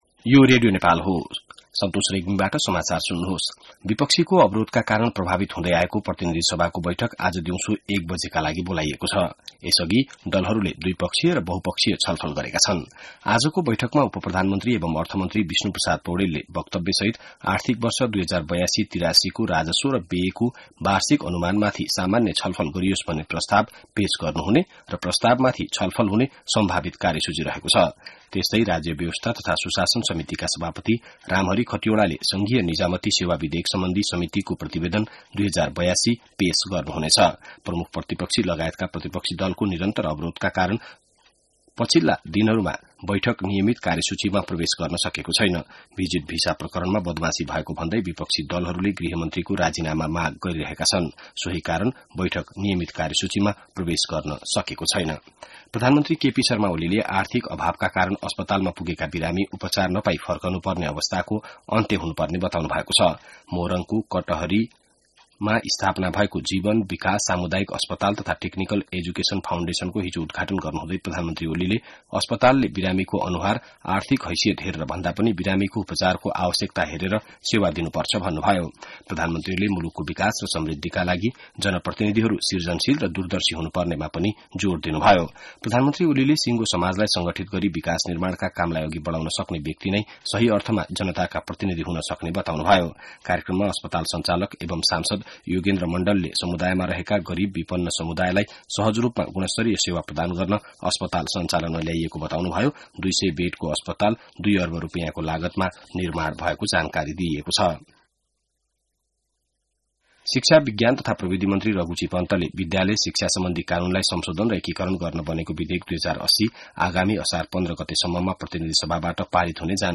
बिहान ६ बजेको नेपाली समाचार : २५ जेठ , २०८२